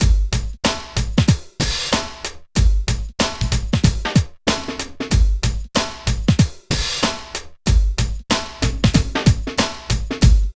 101 LOOP  -L.wav